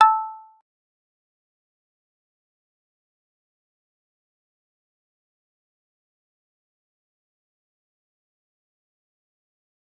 G_Kalimba-A5-pp.wav